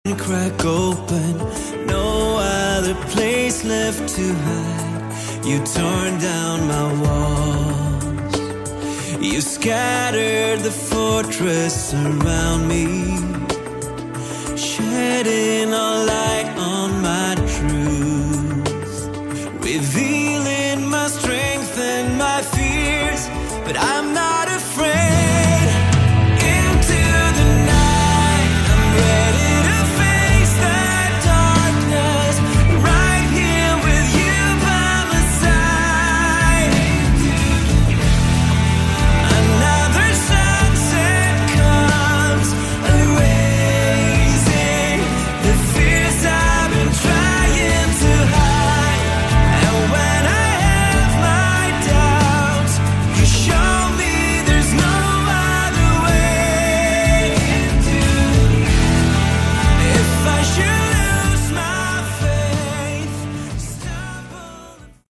Category: AOR
guitar, vocals, keyboards
bass
drums